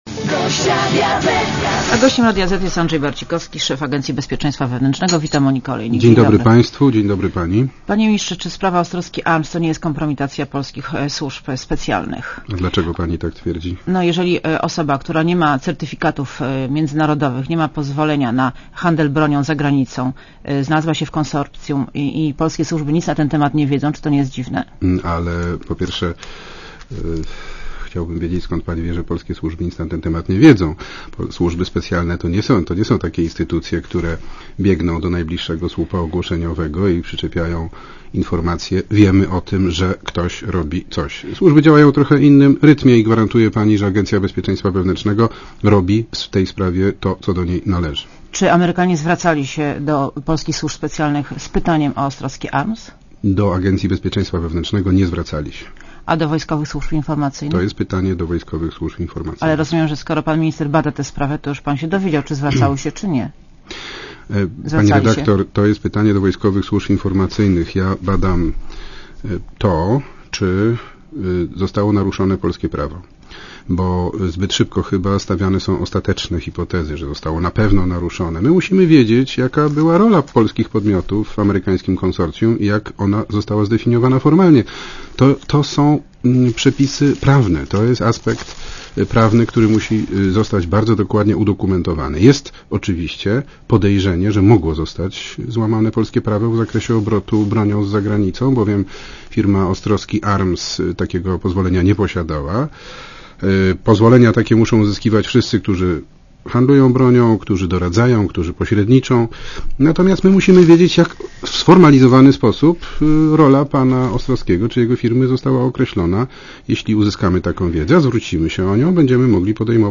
Posłuchaj wywiadu Moniki Olejnik z Andrzejem Barcikowskim (2,6 MB)